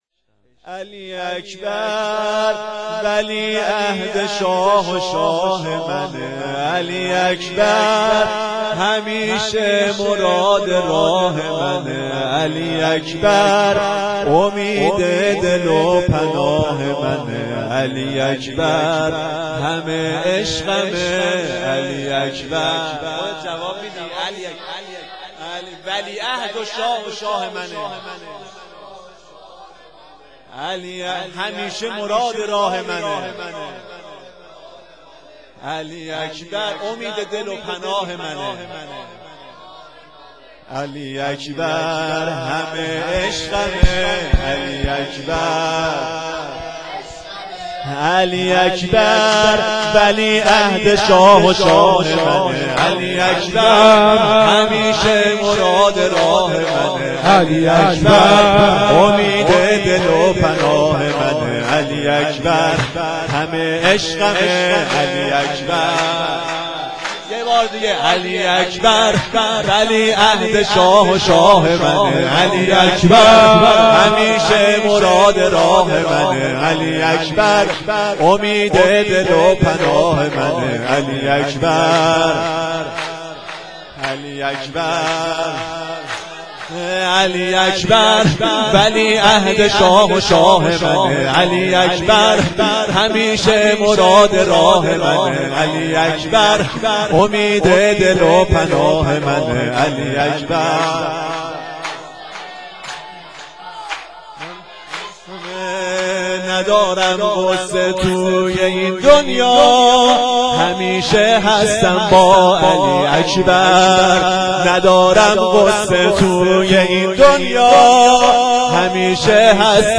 سرود
شام میلاد حضرت علی اکبر 1392